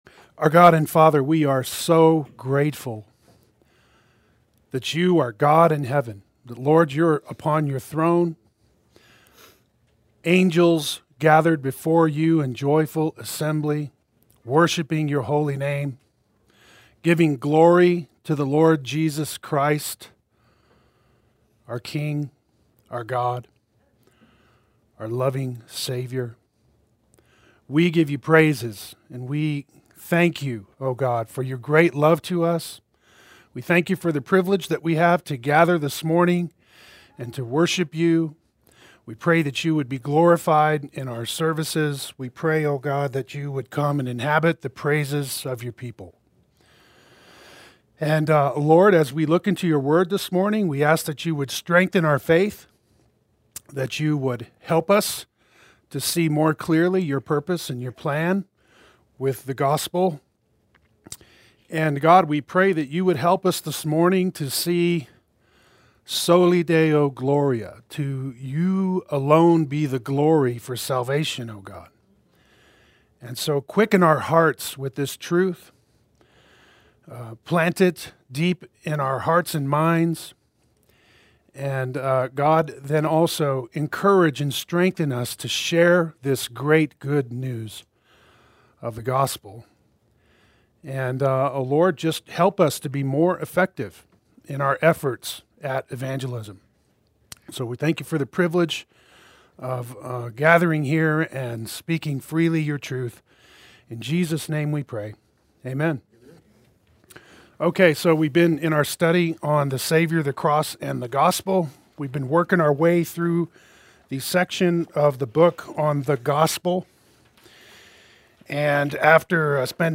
Play Sermon Get HCF Teaching Automatically.
God’s Sovereignty in Salvation Adult Sunday School